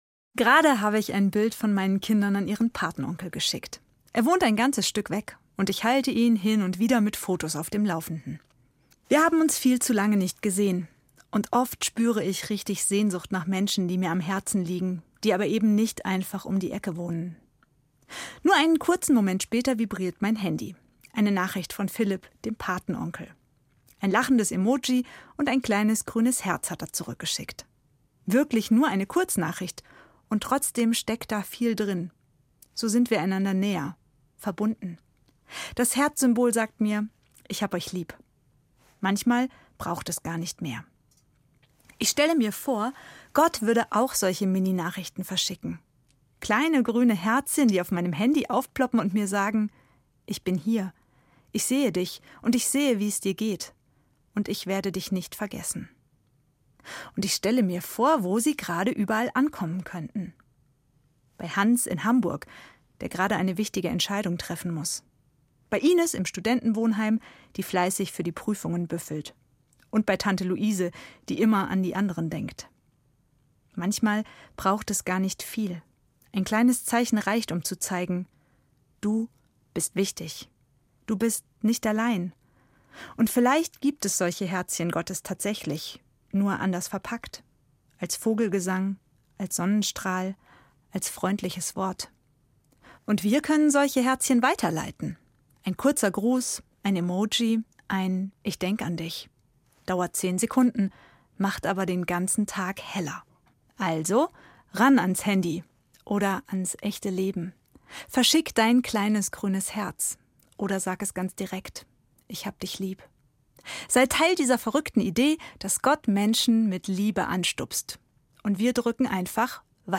Ev. Pfarrerin in Fulda